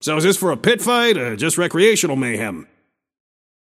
Shopkeeper voice line - So is this for a pit-fight, or just recreational mayhem?